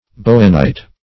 Search Result for " bowenite" : The Collaborative International Dictionary of English v.0.48: Bowenite \Bow"en*ite\, n. [From G.T. Bowen, who analyzed it in 1822.]